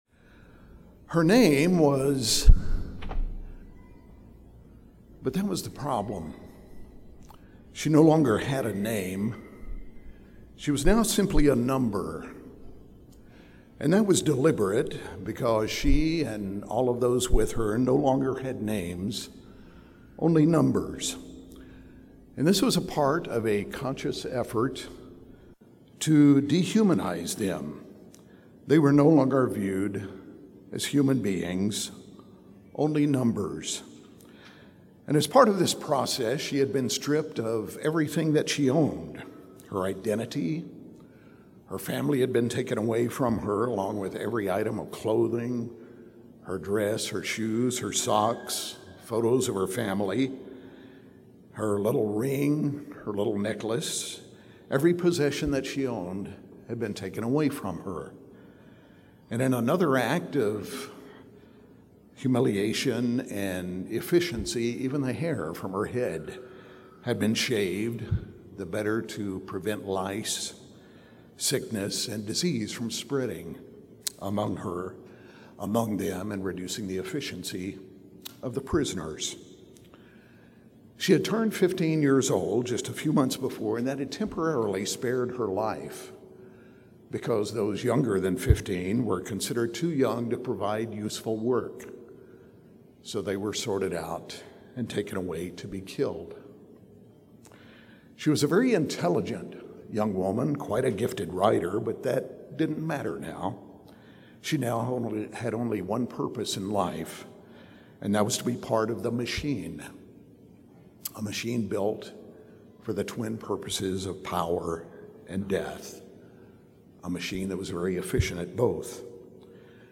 This sermon was given at the Gatlinburg, Tennessee 2024 Feast site.